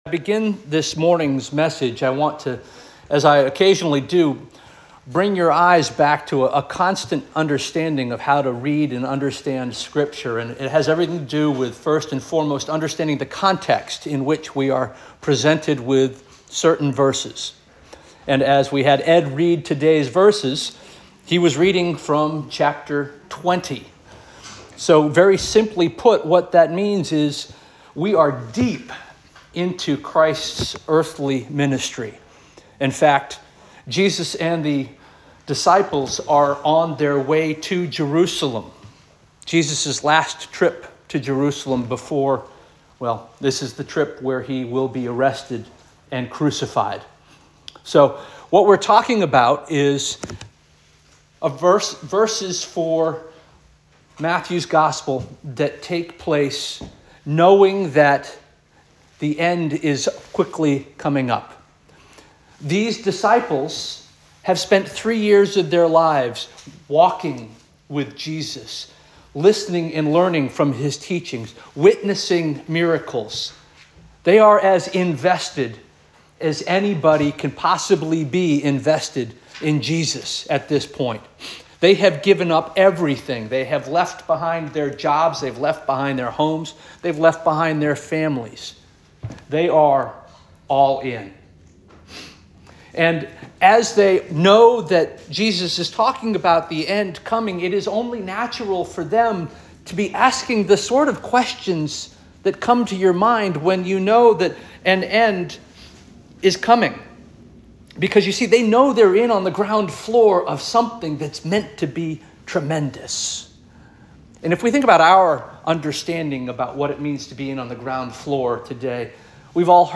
February 2 2025 Sermon